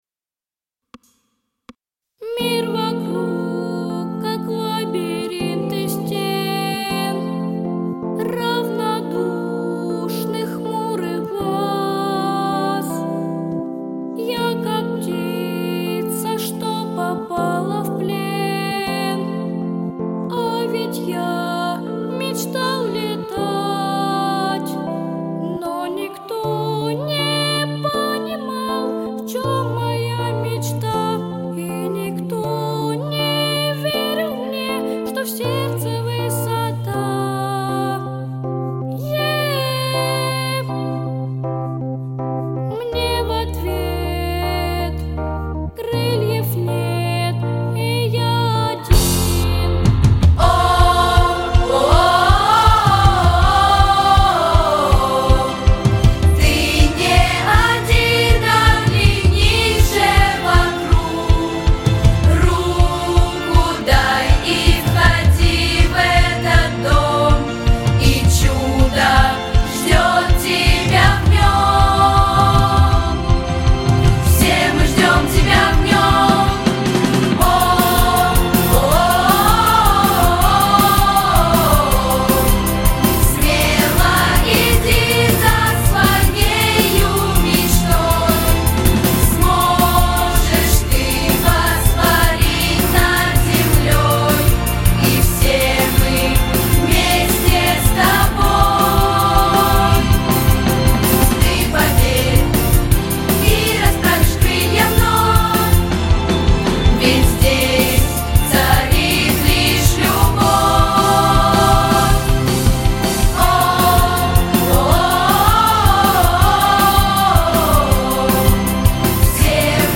• Категория: Детские песни